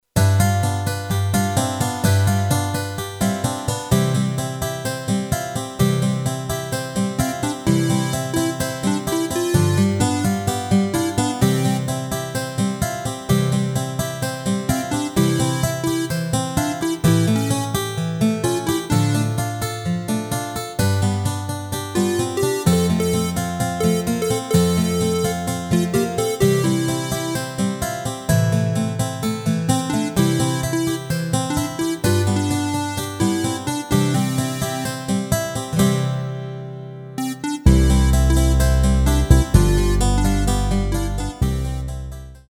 Rubrika: Pop, rock, beat